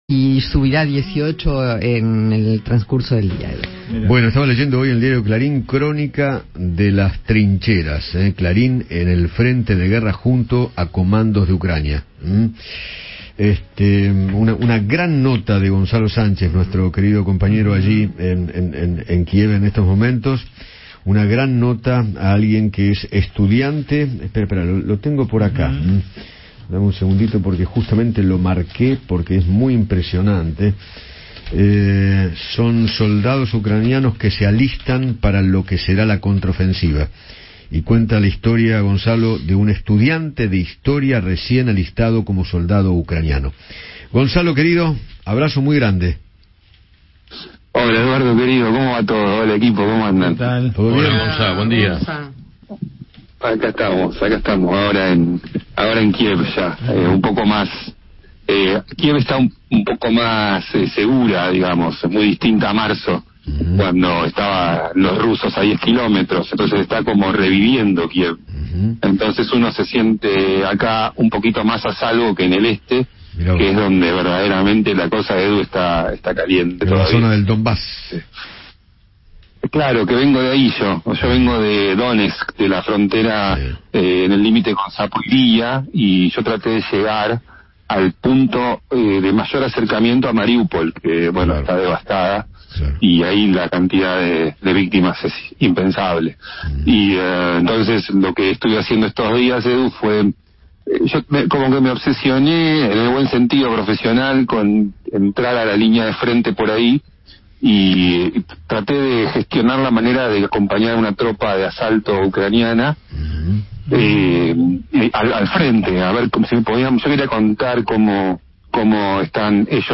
dialogó con Eduardo Feinmann desde Ucrania y detalló cómo continúa la vida después de 76 días de guerra.